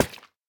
1.21.4 / assets / minecraft / sounds / dig / coral2.ogg
coral2.ogg